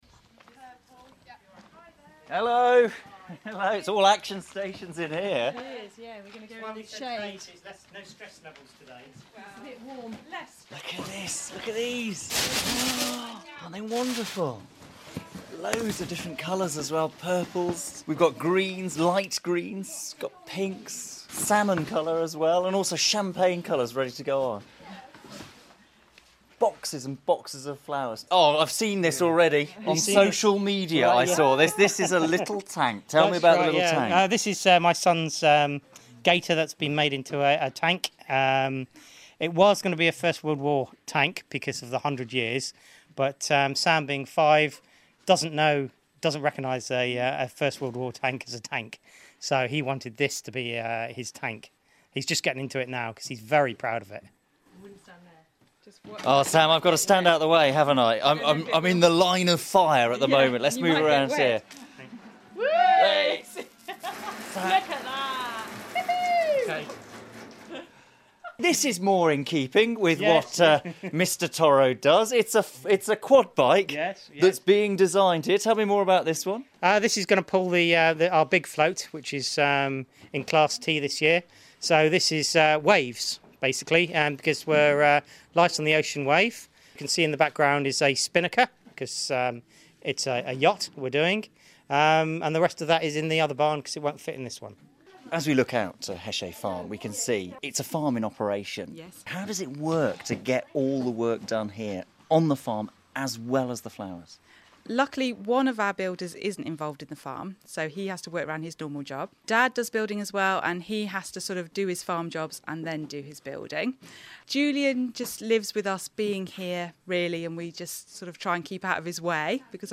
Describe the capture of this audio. BBC Radio Guernsey headed out to La Hechet Farm